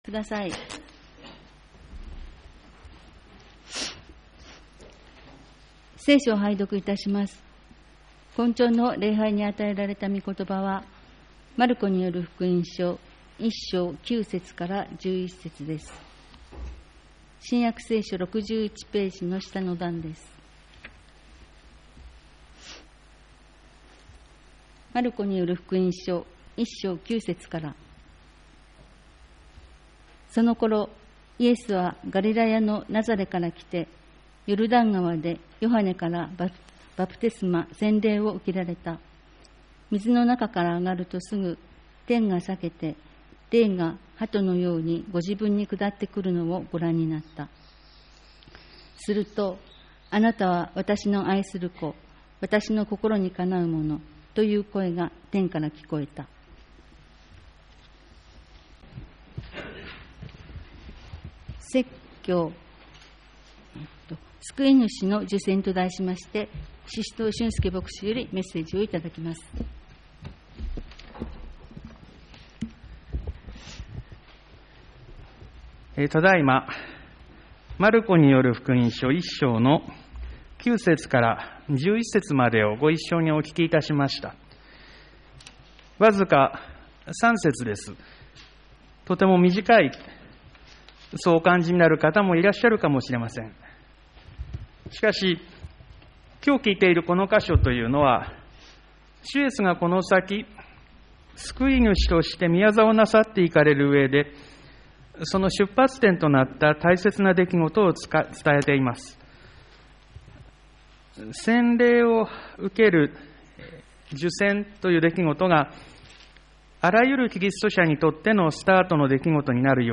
■ ■ ■ ■ ■ ■ ■ ■ ■ 2021年6月 6月6日 6月13日 6月20日 6月27日 毎週日曜日の礼拝で語られる説教（聖書の説き明かし）の要旨をUPしています。
救い主の受洗 2021年6月第1主日礼拝